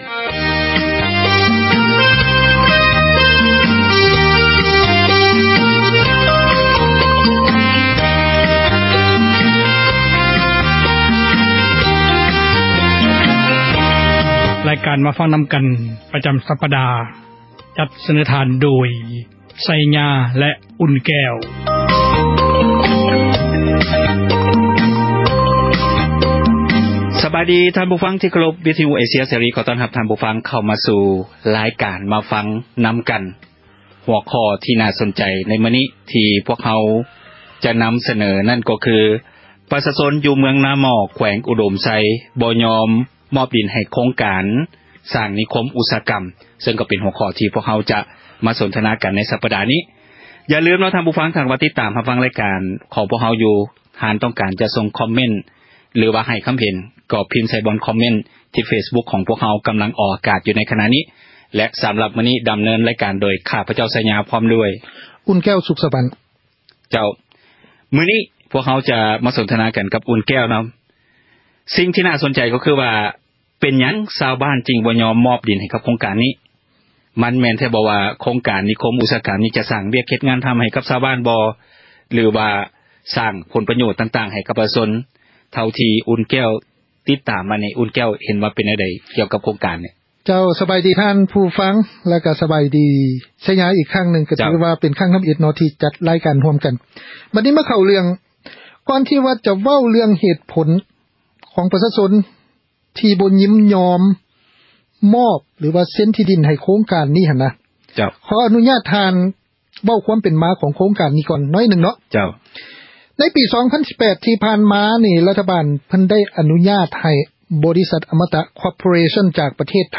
ແມ່ນຣາຍການສົນທະນາ ບັນຫາສັງຄົມ